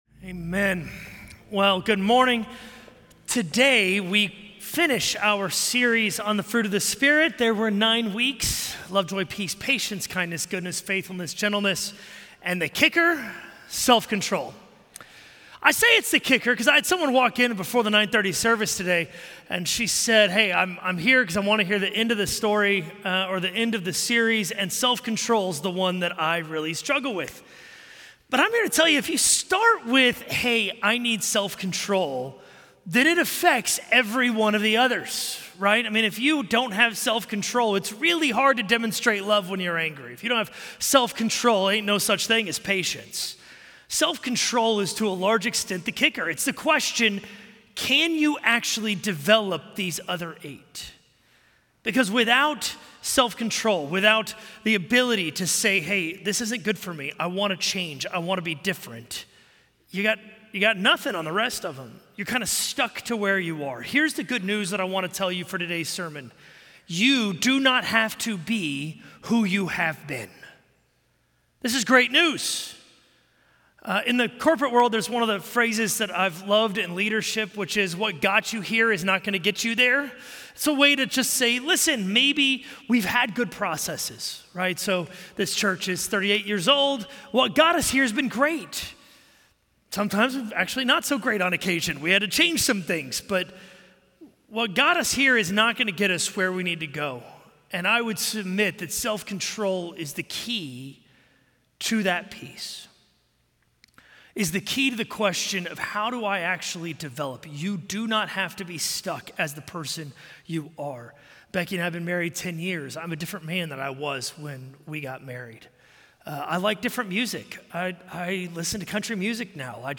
A message from the series "Fruit of the Spirit."